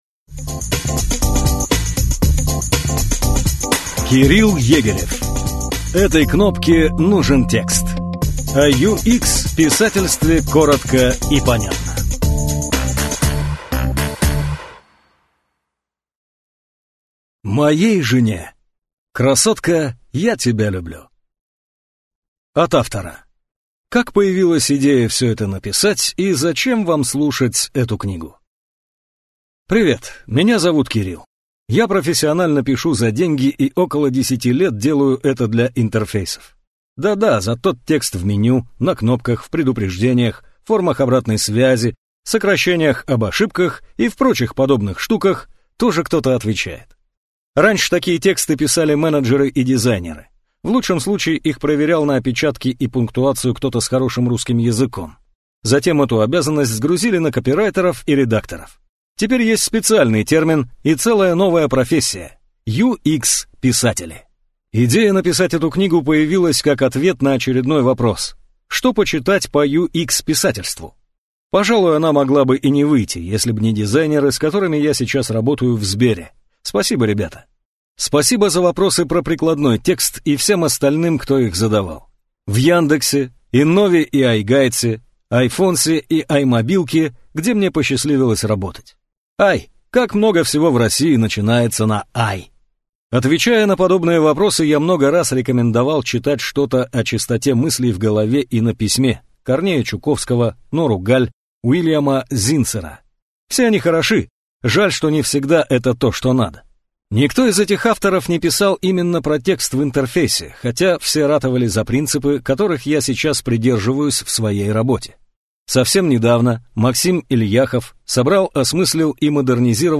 Аудиокнига Этой кнопке нужен текст. O UX-писательстве коротко и понятно | Библиотека аудиокниг